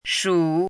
shǔ
拼音： shǔ
注音： ㄕㄨˇ
shu3.mp3